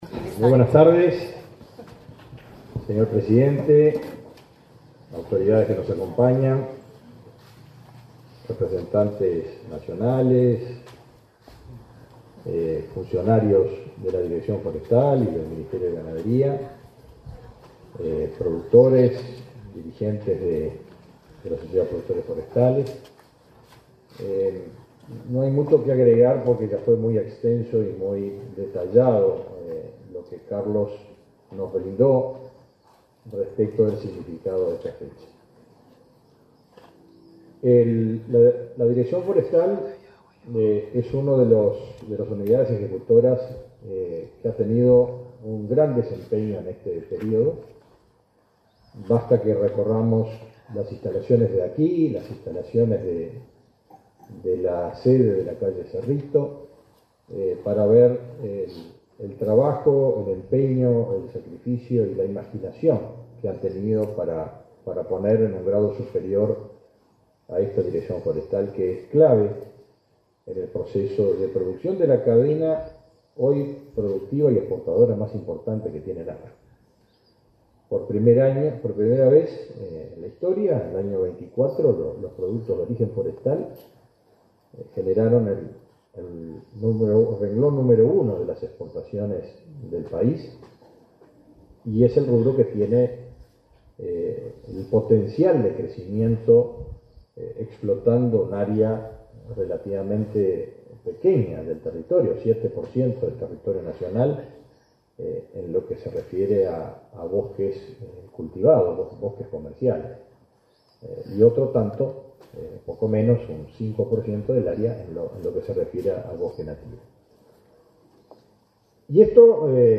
Palabras del ministro de Ganadería, Agricultura y Pesca, Fernando Mattos
En la conmemoración de los 60 años de la Dirección General Forestal, este 12 de febrero, se expresó el ministro de Ganadería, Agricultura y Pesca,
Mattos acto.mp3